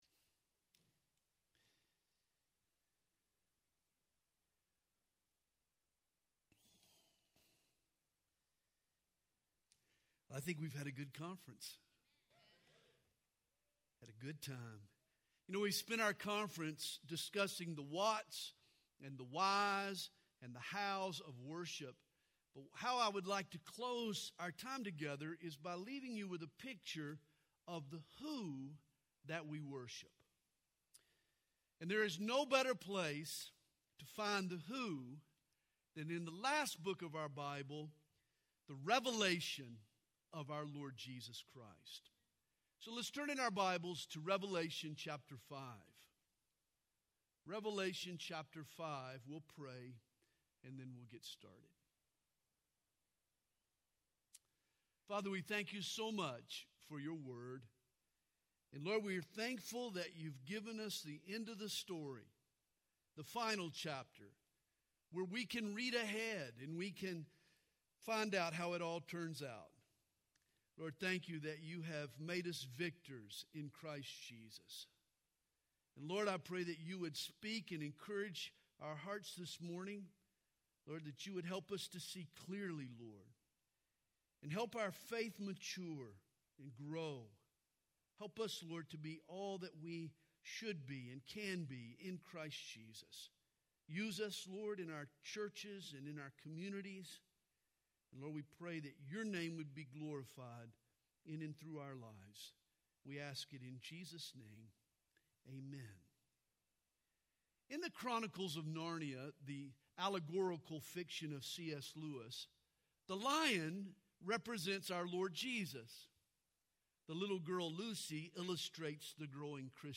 Home » Sermons » DSWC 2023 – Session 6